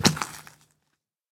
Minecraft Version Minecraft Version snapshot Latest Release | Latest Snapshot snapshot / assets / minecraft / sounds / mob / zombie / step1.ogg Compare With Compare With Latest Release | Latest Snapshot
step1.ogg